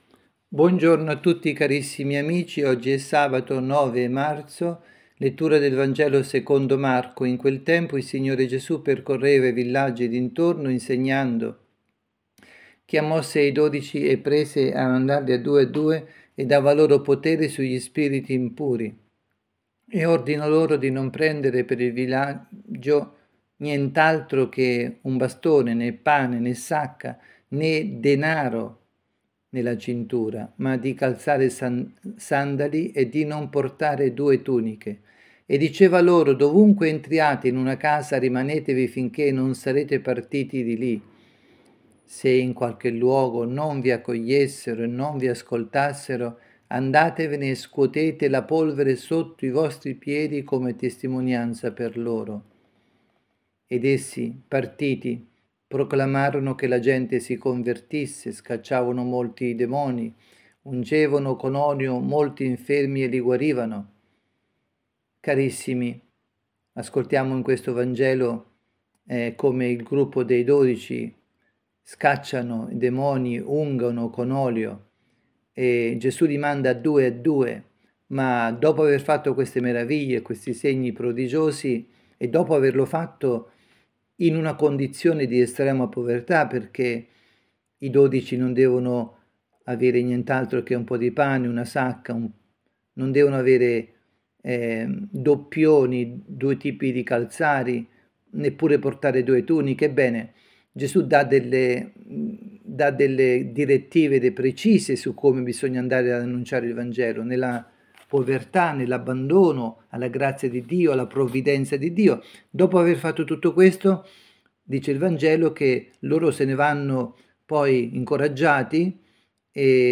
avvisi, Catechesi, Omelie, Quaresima
dalla Parrocchia Santa Rita – Milano